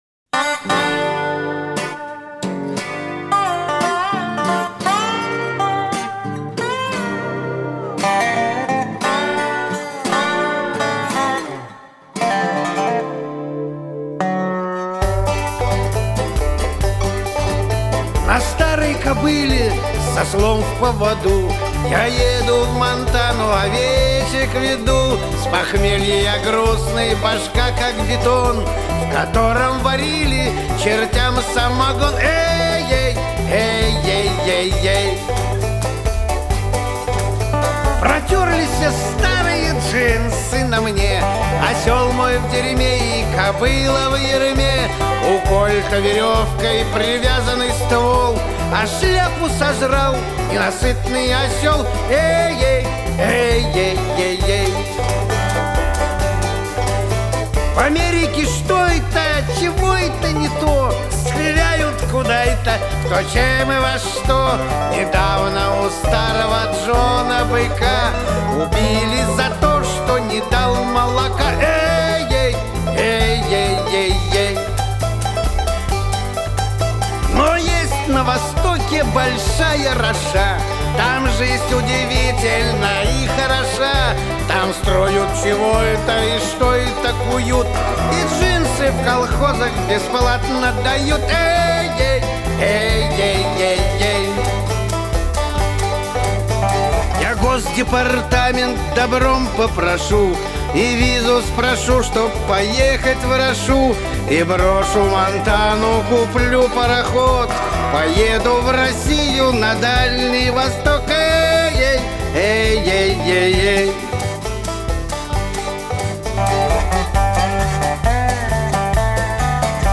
А как она красиво поёт, умница!
Там на западе....Кантри...
Очень весёлая.